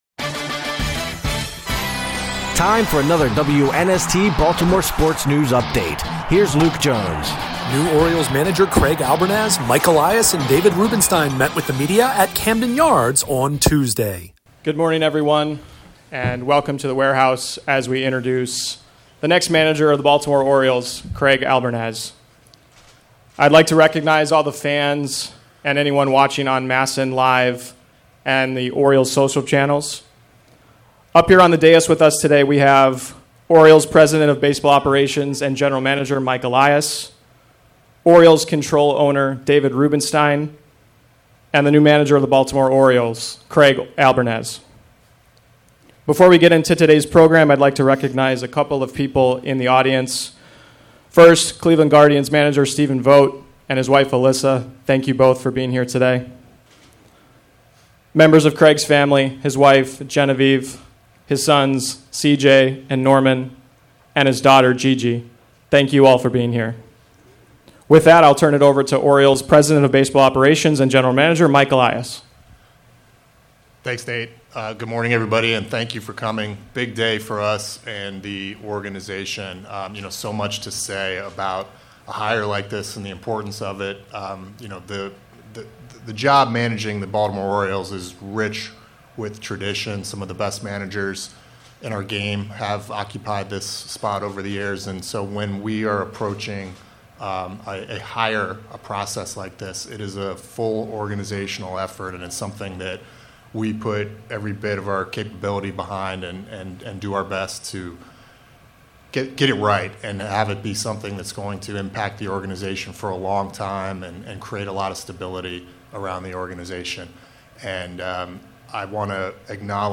Craig Albernaz, Mike Elias, David Rubenstein meet with local media at Camden Yards